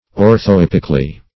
-- Or`tho*ep"ic*al*ly , adv.